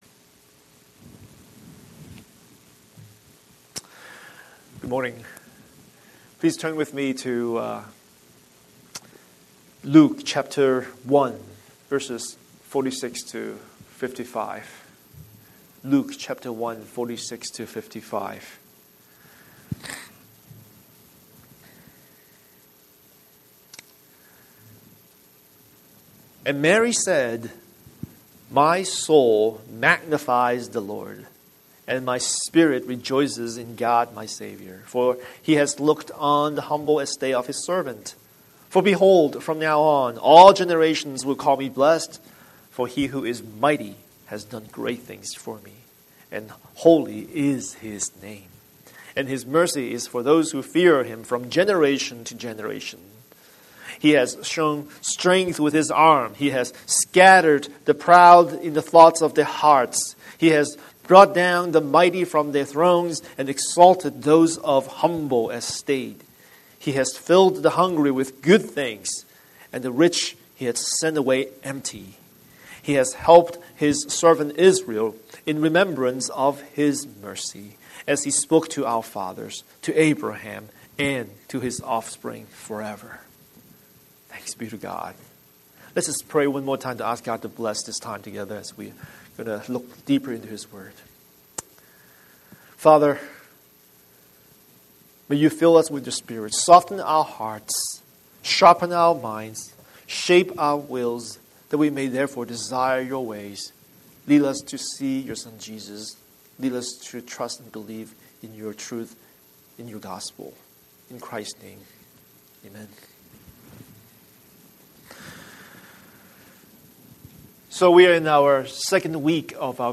Scripture: Luke 1:46-55 Series: Sunday Sermon